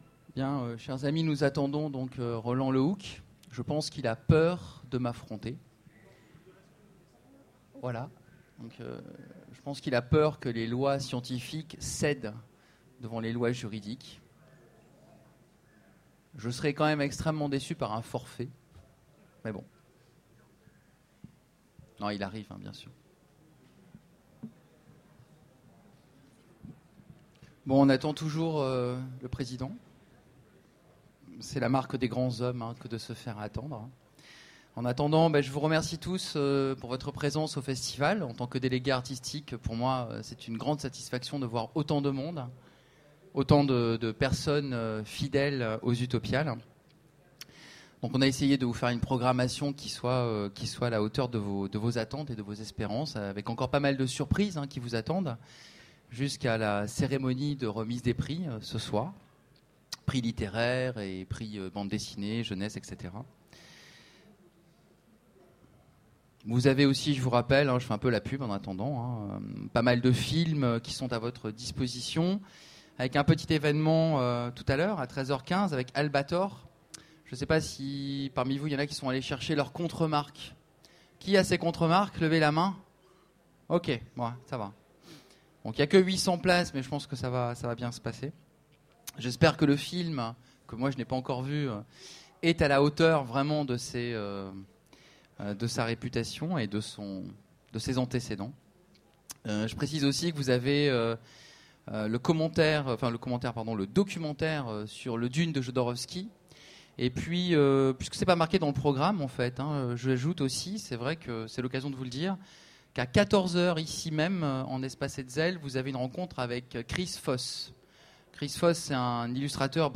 Utopiales 13 : Conférence Lois juridiques vs lois physiques